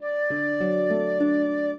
flute-harp
minuet15-4.wav